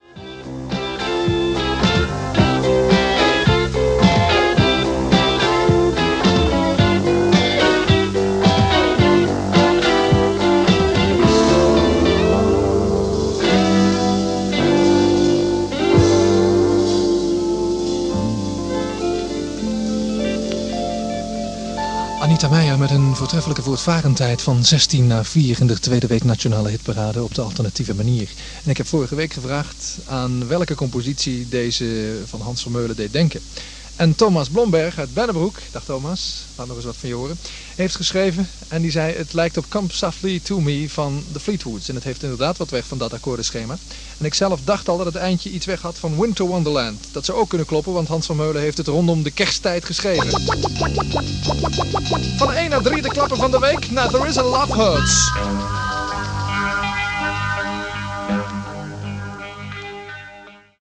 Fragment uit de Nationale Hitparade van 12 maart 1976